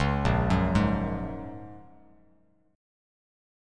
Bbm7#5 chord